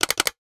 NOTIFICATION_Click_01_mono.wav